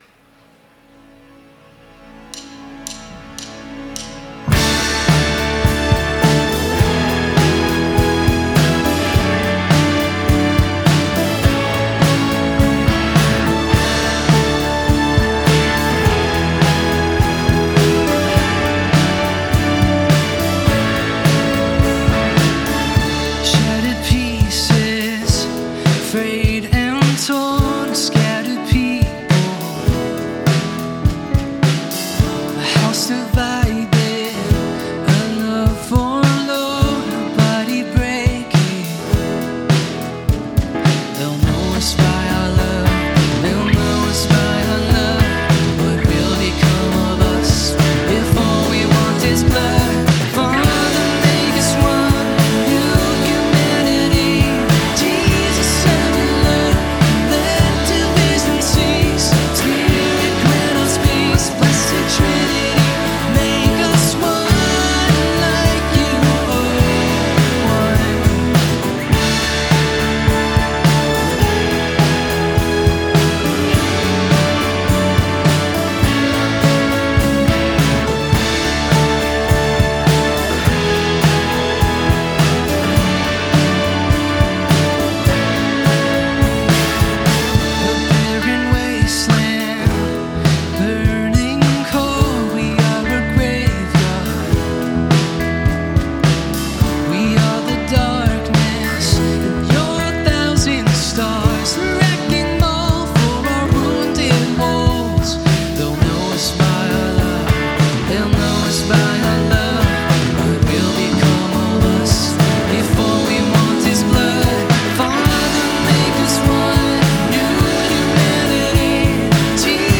Here are some of the musical adventures of the worship team that I've mixed in post-production